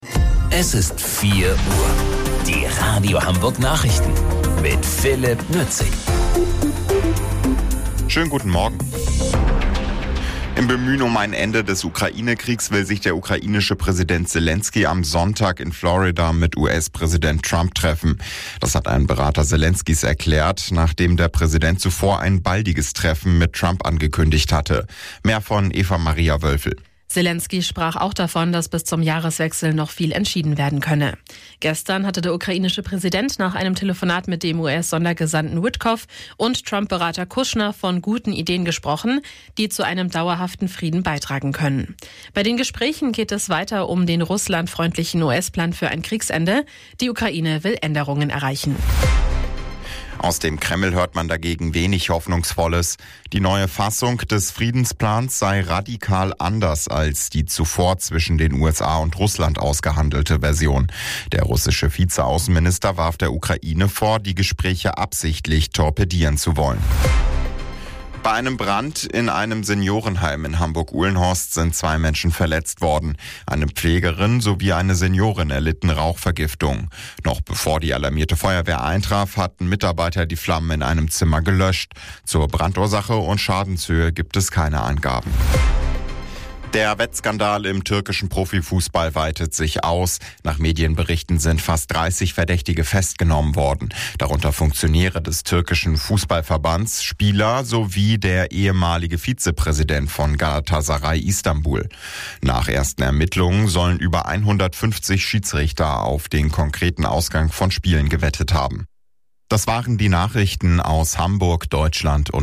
Radio Hamburg Nachrichten vom 27.12.2025 um 04 Uhr